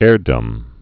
(ârdəm)